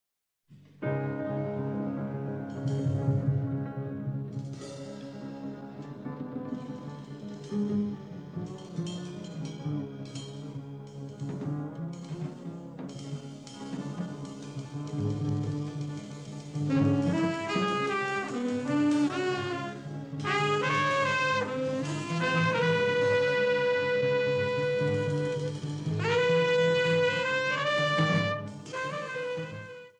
piano
saxophones
trumpet
bass
drums